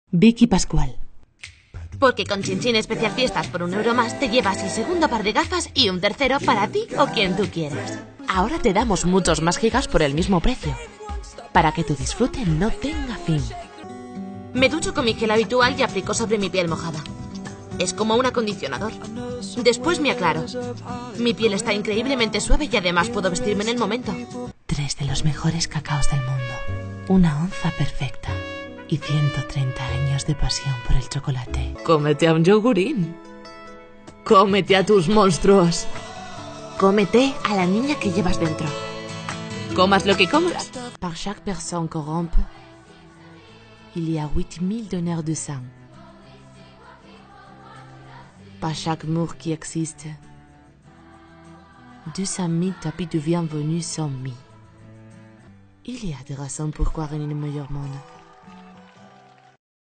Voz de un rango entre los 15 años y los 55. Posibilidad de hacer niños y niñas pequeños. Puedo hacer desde un anuncio con voz sexy y cálida hasta algo muy rápido y completamente cantado. Puedo poner voz clásica de locutora o salir de esos registros y anunciarte todo con un tono de calle, mas neutral. Realizo también presentaciones y todo lo que se te ocurra! _____________________________________________________________ Voice of a range between 15 years and 55. Possibility of making small children. I can do from an ad with a sexy and warm voice to something very fast and completely sung. I can put the classic voice of announcer or leave those records and announce everything with a tone of street, more neutral. I also make presentations and everything you can think of!
Sprechprobe: Werbung (Muttersprache):